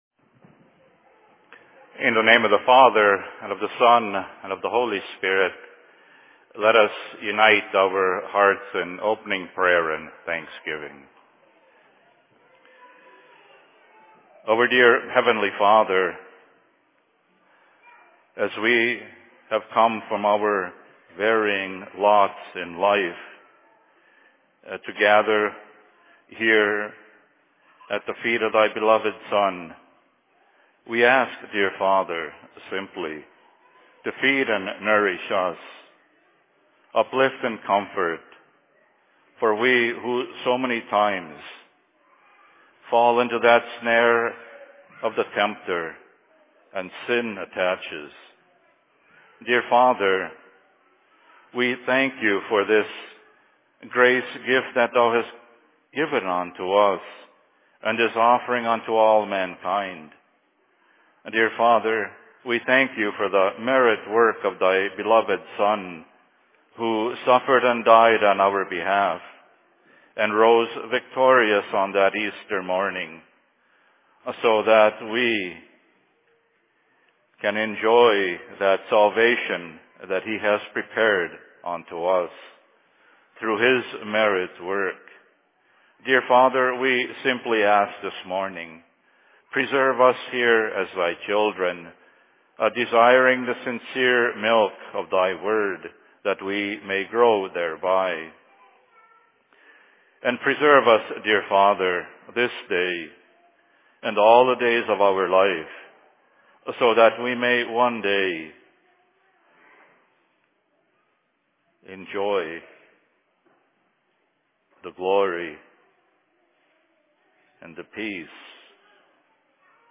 Sermon in Cokato 14.02.2016